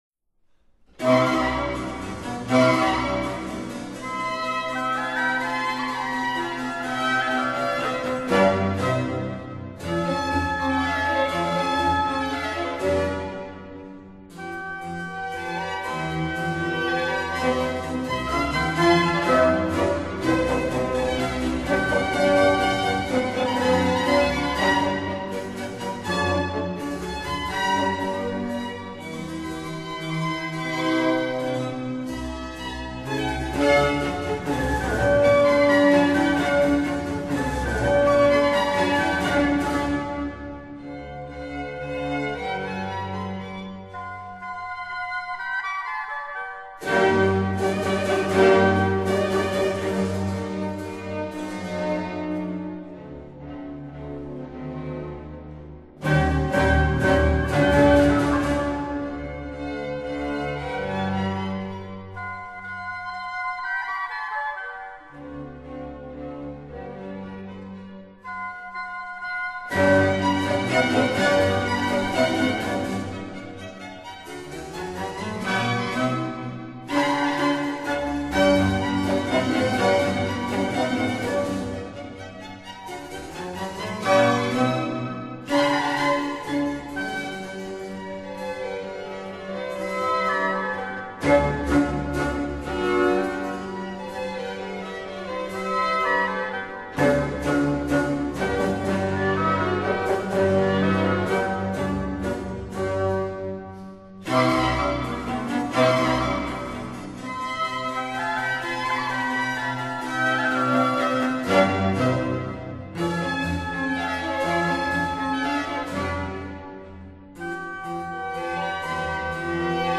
以生動的節奏和特異的古樂音響作為他們呈現罕見古代樂曲的主力
他們都是新一代義大利古樂學派的演奏家